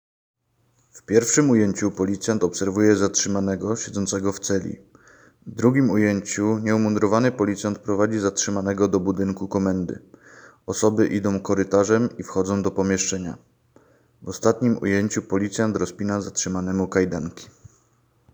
Nagranie audio Audiodeskrypcja_zatrzymany.m4a